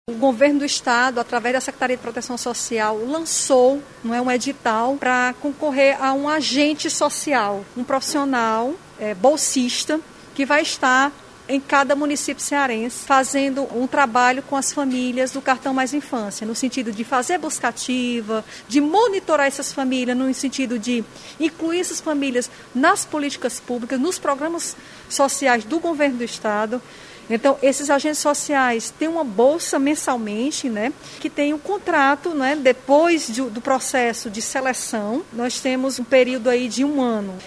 Onélia Santana fala sobre o edital e o a importância dos agentes sociais.